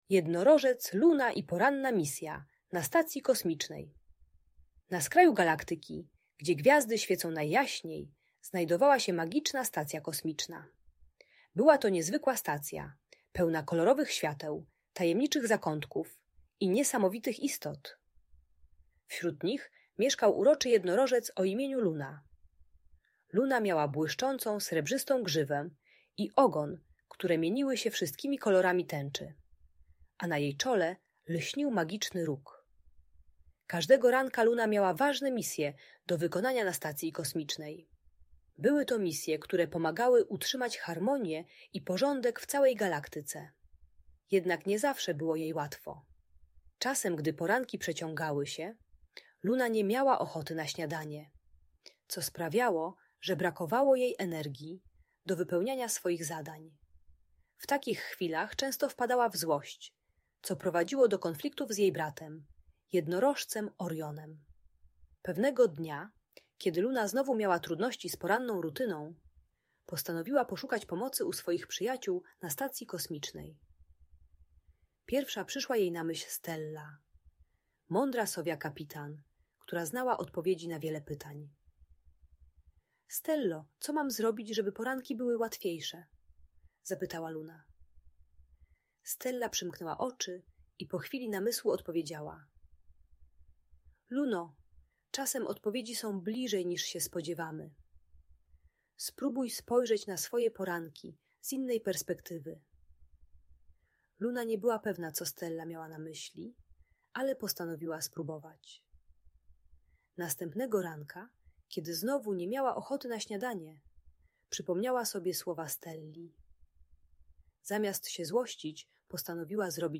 Historia Jednorożca Luny i Porannej Misji na Stacji Kosmicznej - Audiobajka dla dzieci